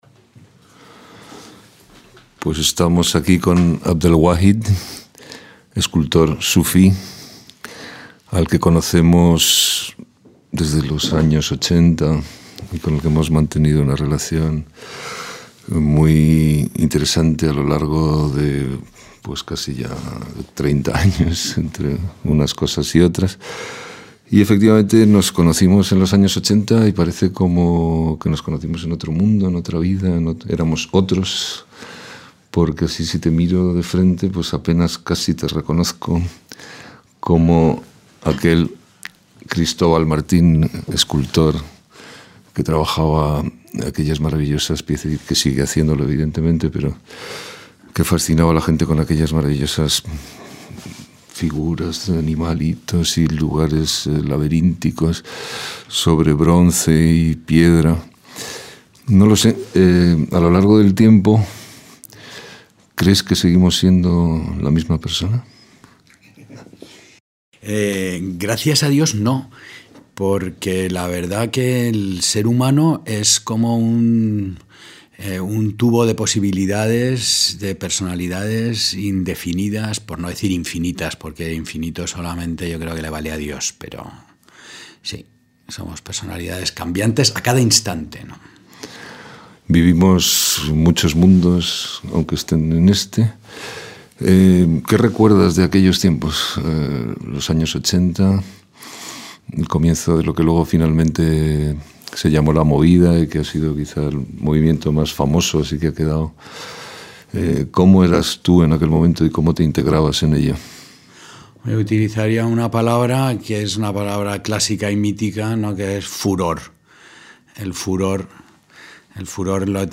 Conversaciones